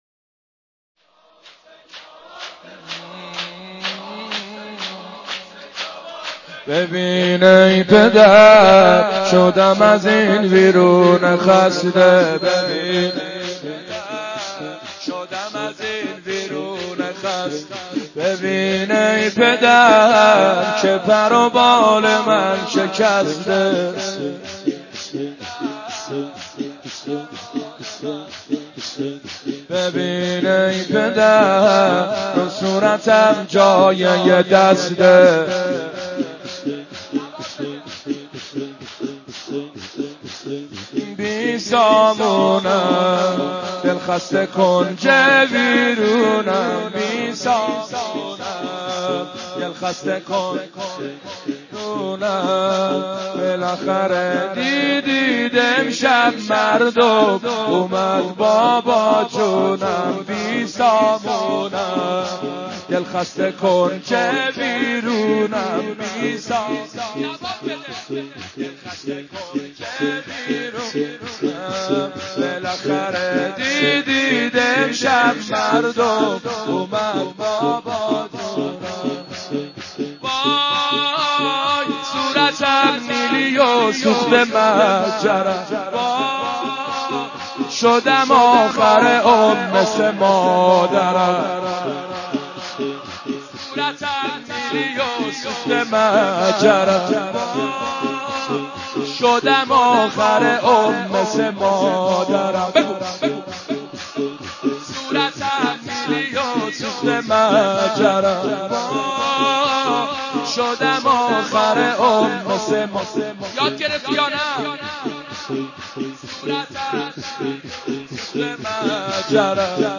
متن سینه زنی شور شهادت حضرت رقیه (س)